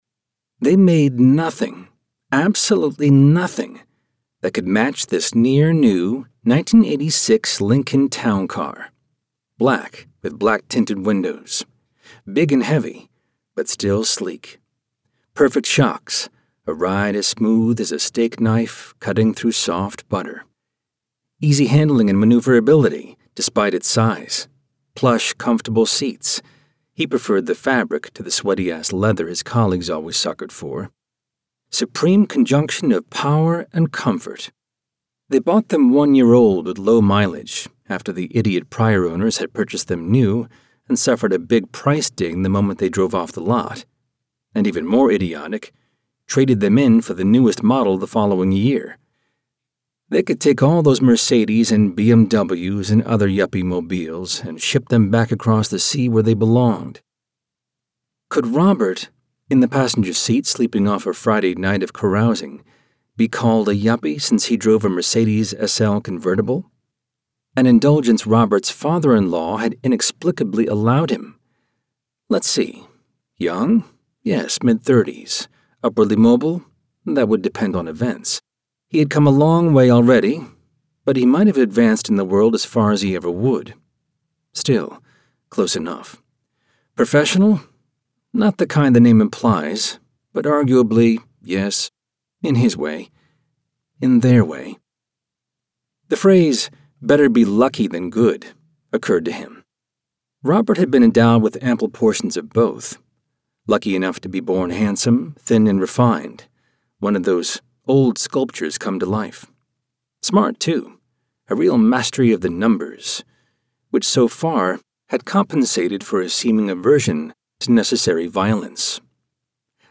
• Audiobook • 09 hrs 30 min